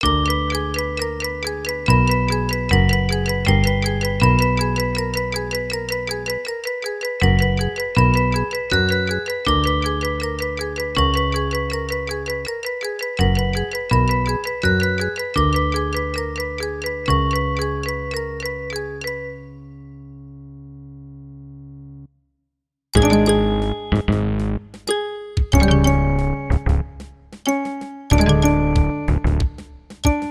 Converted from .mid to .ogg
Fair use music sample